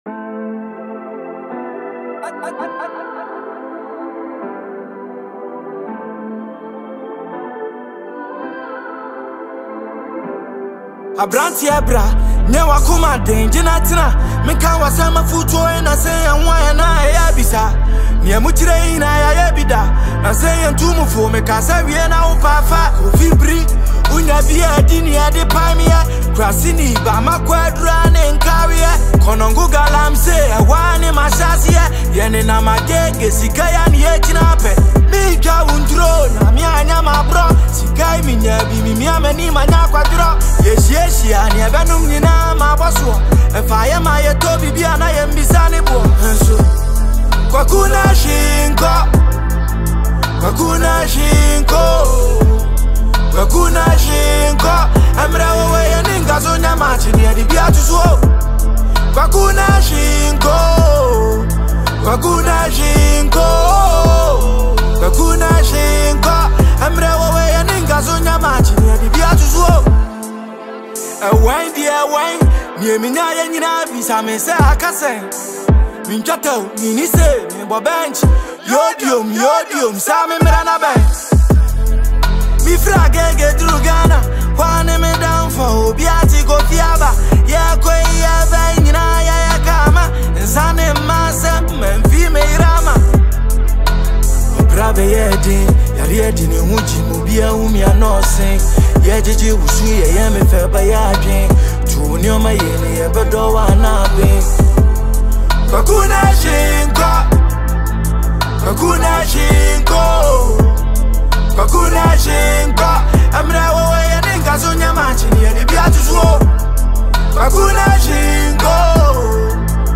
hip-hop anthem
Known for his distinct voice and fearless lyrical delivery